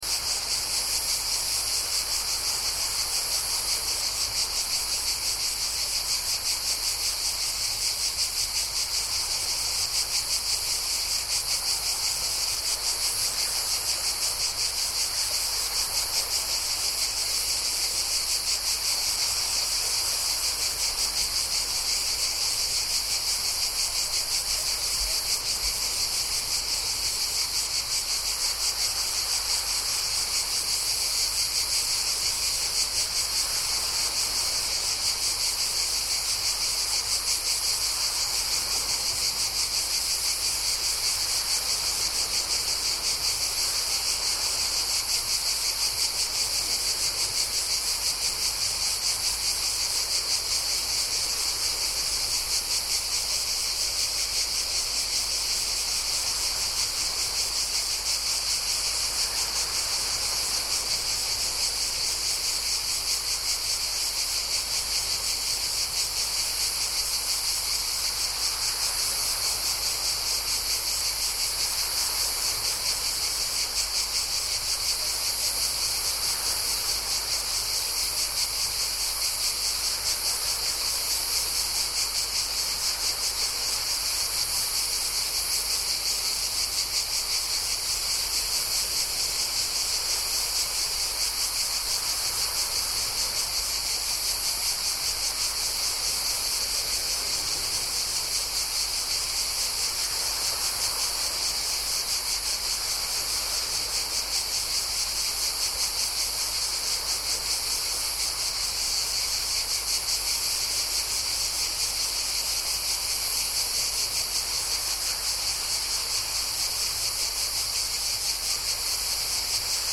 Cicadas Sound Effect – 3D Ambisonic Nature Audio
Loud cicadas chirp in a Mediterranean forest near the sea, while gentle ocean waves softly crash against the shore in the background. This soundscape delivers the hot summer ambience of the Mediterranean coast—ideal for nature scenes, documentaries, travel videos, and immersive audio projects. Captured with professional audio equipment optimized for ambisonic format recording.
Cicadas-sound-effect-3d-ambisonic-nature-audio.mp3